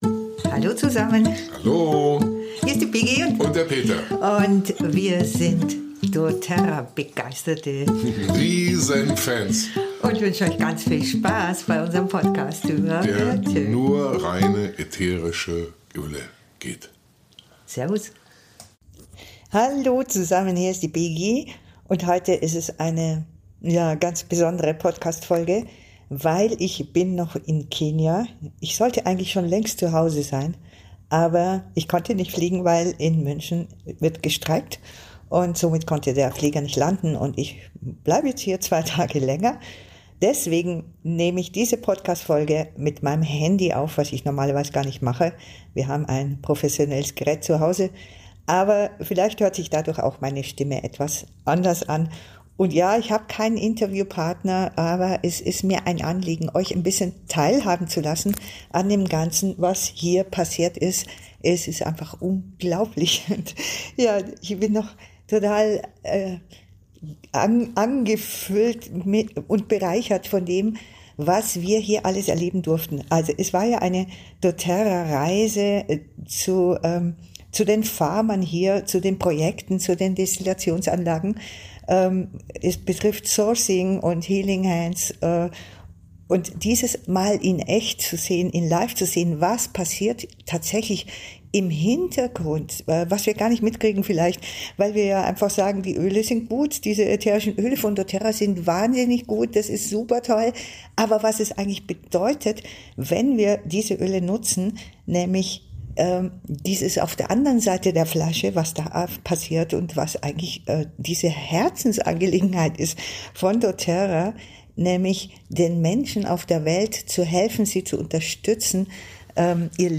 Ich musste einfach gleich noch meine Eindrücke mit dem Handy aufnehmen..